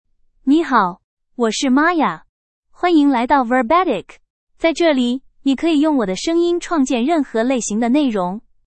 Maya — Female Chinese (Mandarin, Simplified) AI Voice | TTS, Voice Cloning & Video | Verbatik AI
Maya is a female AI voice for Chinese (Mandarin, Simplified).
Voice sample
Listen to Maya's female Chinese voice.
Female
Maya delivers clear pronunciation with authentic Mandarin, Simplified Chinese intonation, making your content sound professionally produced.